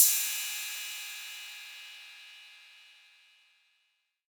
808CY_3_Orig_ST.wav